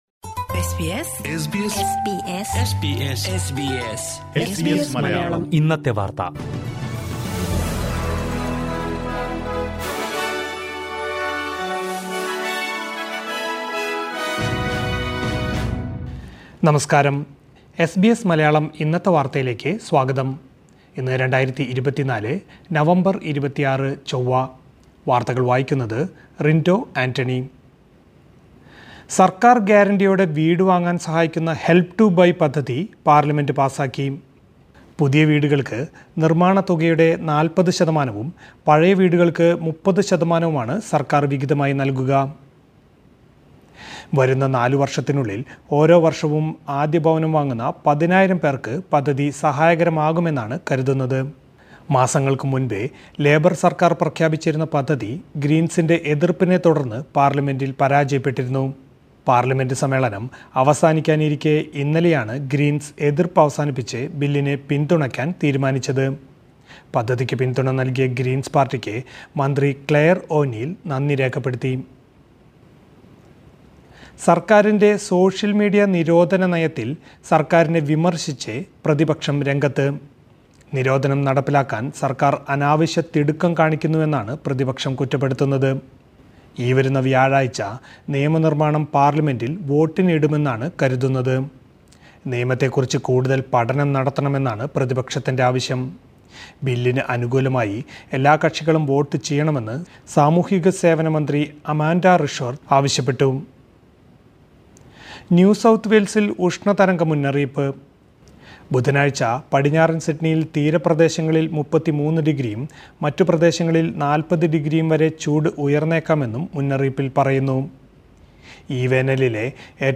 2024 നവംബർ 26ലെ ഓസ്ട്രേലിയയിലെ ഏറ്റവും പ്രധാന വാർത്തകൾ കേൾക്കാം...